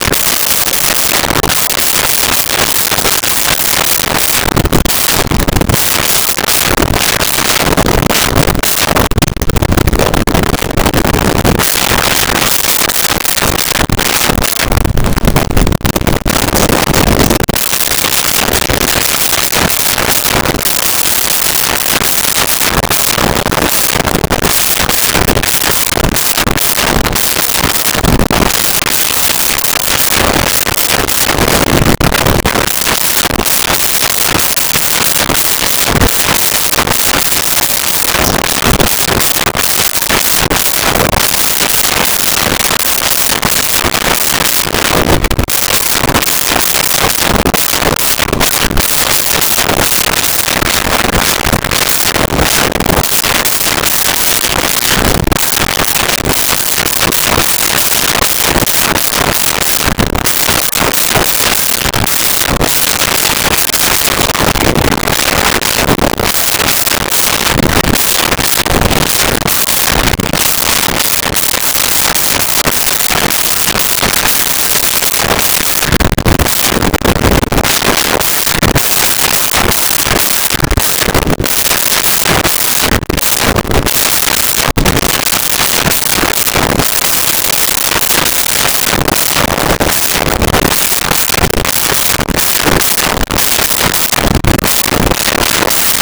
Kennel Interior
Kennel Interior.wav